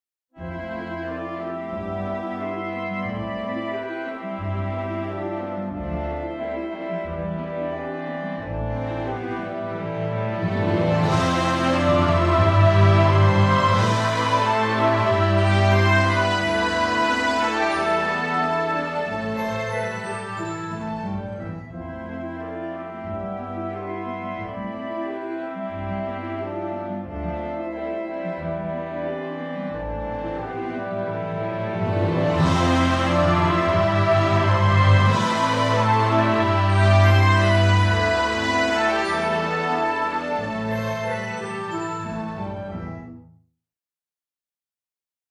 Hi! I made a quick orchestration